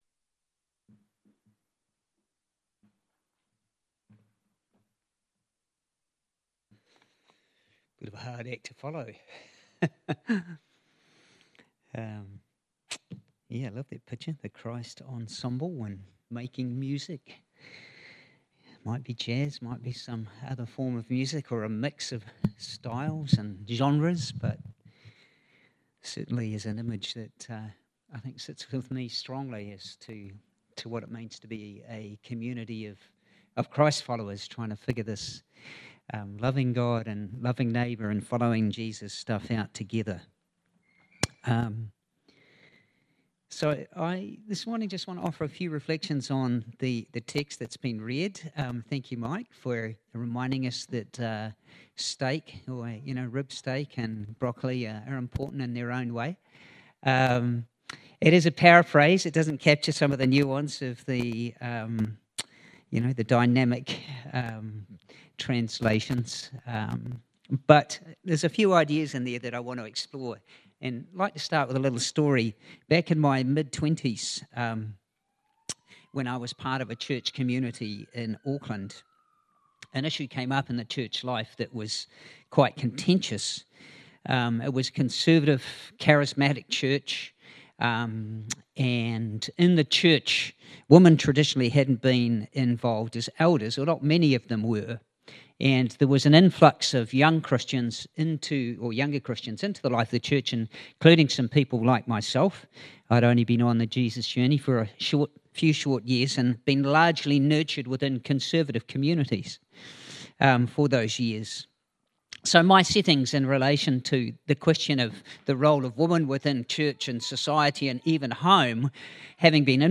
The sermon was recorded in two parts as there was a Q&A partway through. These have been combined and some silent parts trimmed.